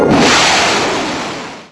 Missile sounds
Or you can just have the modified version, I guess it will work good with fast firing like fast rocket artillery or whatever
missile5 mdfyd.wav
missile5_mdfyd_798.wav